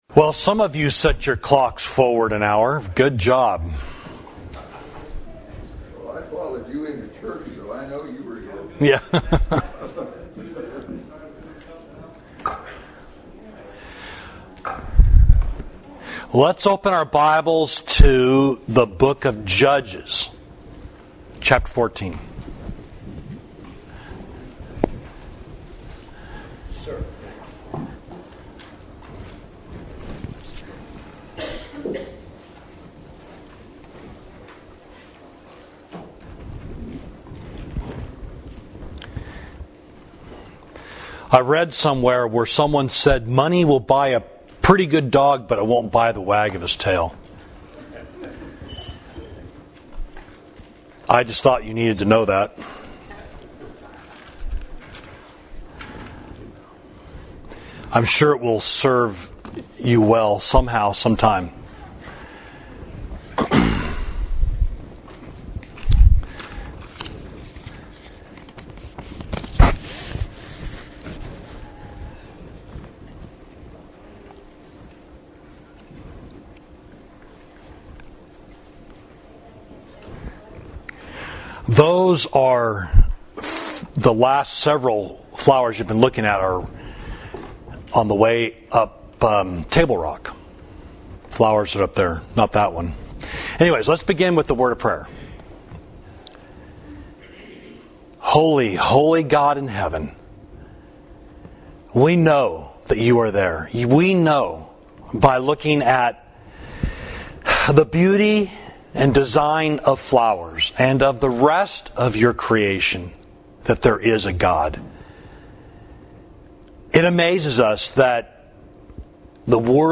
Class: A Man of Strength and Poetry, Judges 14